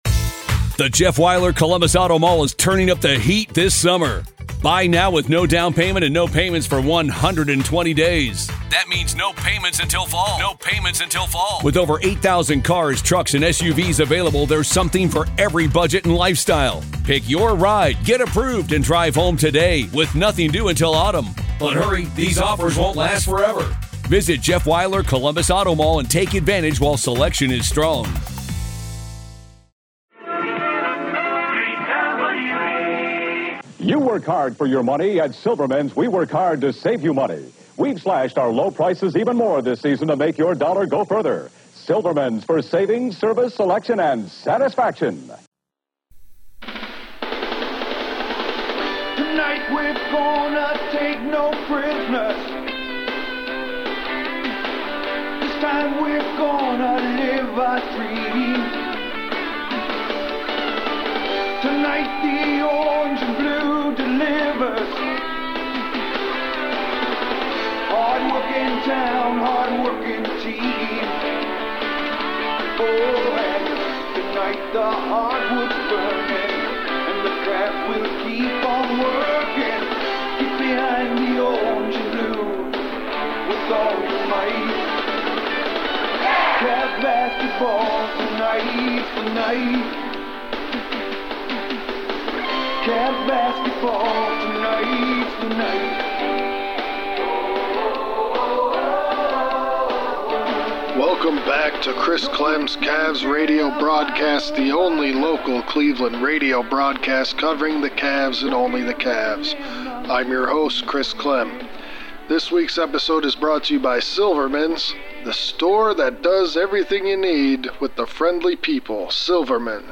It’s the first rerun of the C4 Podcast! (This episode was originally broadcast 4/1/93 and is presented in its original form.)